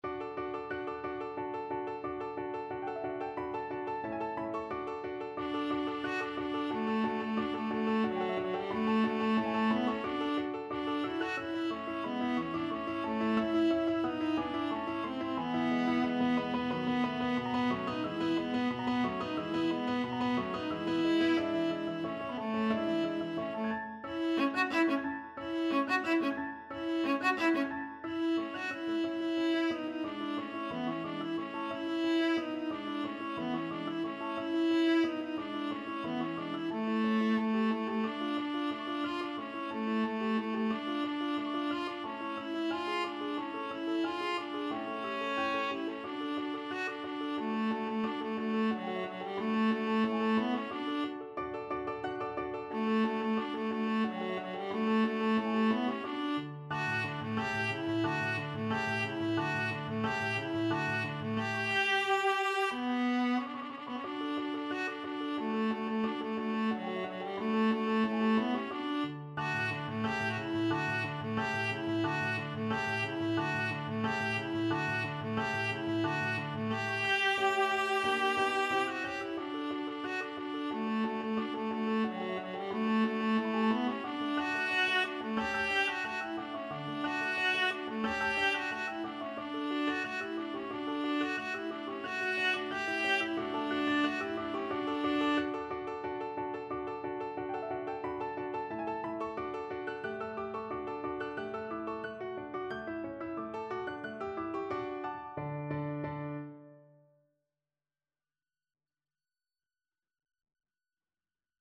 D major (Sounding Pitch) (View more D major Music for Viola )
Presto =180 (View more music marked Presto)
2/4 (View more 2/4 Music)
Viola  (View more Intermediate Viola Music)
Classical (View more Classical Viola Music)